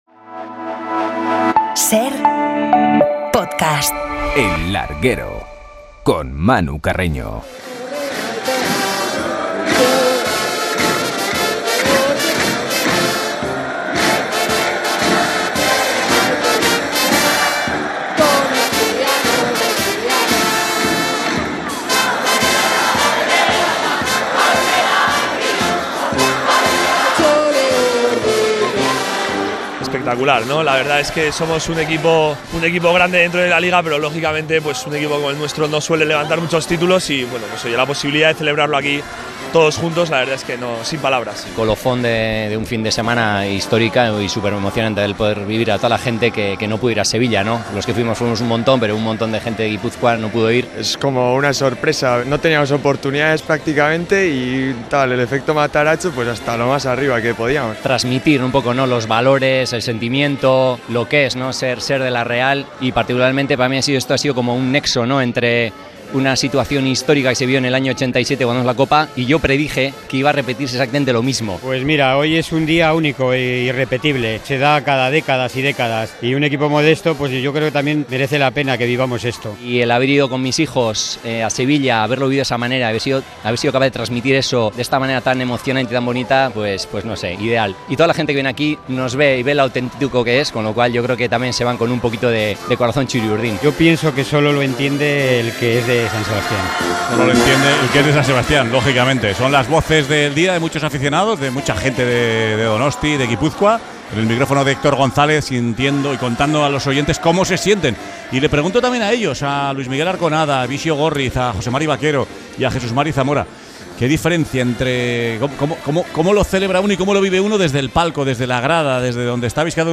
Los héroes de la Copa del ’87 muestran las similitudes y entrevista al alcalde de San Sebastián
Bixio Górriz, Jesús Zamora, José Mari Bakero y Arconada rememoran la hazaña del '87 y la comparan con esta Copa. Además, entrevistamos a Jon Insausti, alcalde de San Sebastián